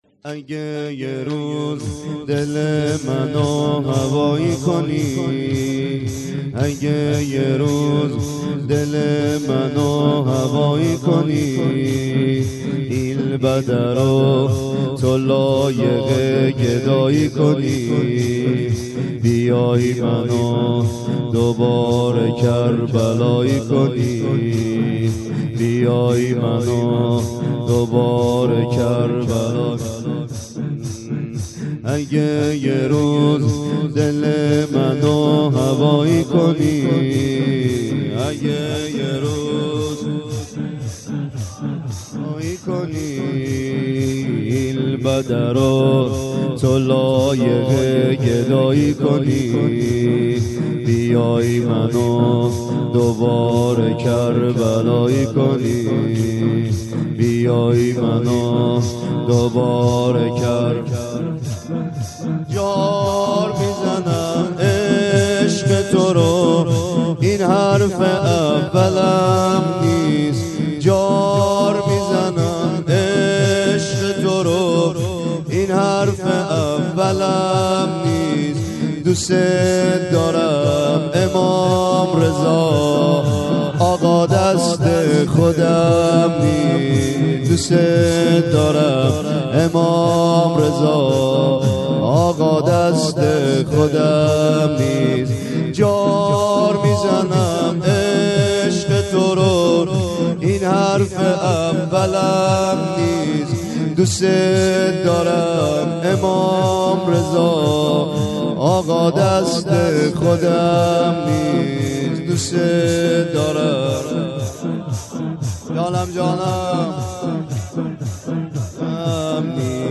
زمینه : اگه یه روز دله منو هوایی کنی